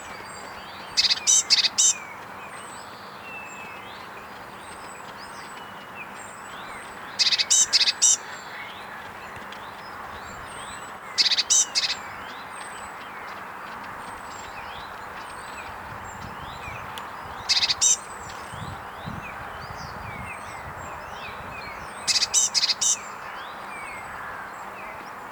Blaumeise Parus caeruleus Blue Tit
Iller bei Seifen OA, 09.03.2013 26 s Rufe